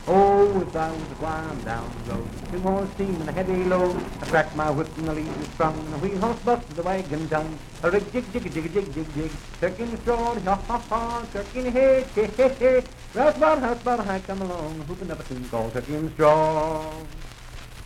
Unaccompanied vocal music
Dance, Game, and Party Songs
Voice (sung)
Wood County (W. Va.), Parkersburg (W. Va.)